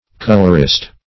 Colorist \Col"or*ist\, n. [Cf. F. coloriste.]